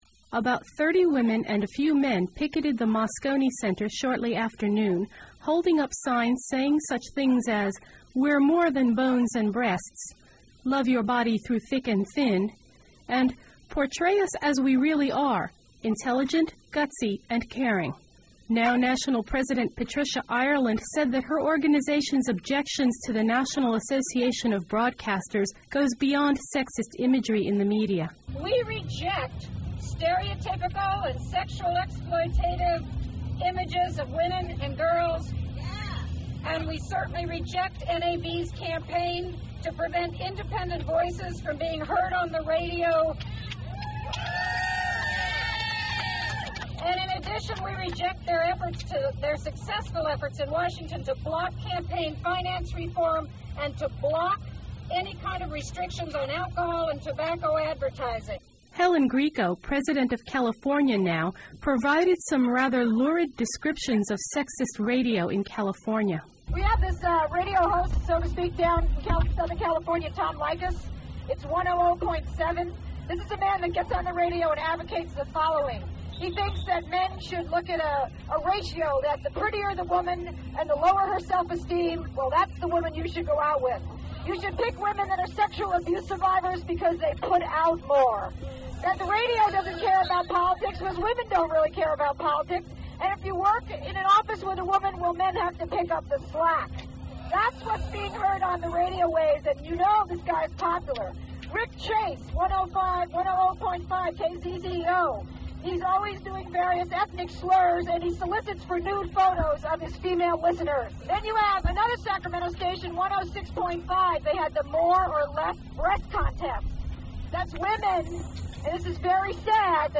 Protests against NAB were kicked off by a NOW picket against NAB. Participants criticized sexism, racism, and homophobia in commercial media (5:34)